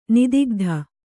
♪ nidigdha